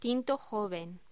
Locución: Tinto joven
voz